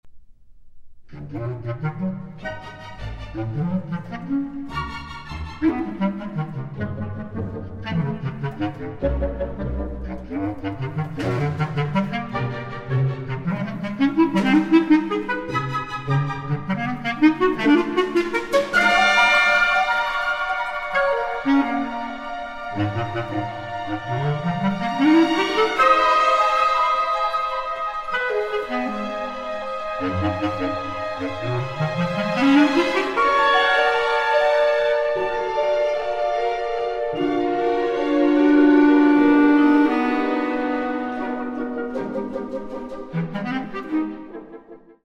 Presto (Scherzo-Rondo) (3:56)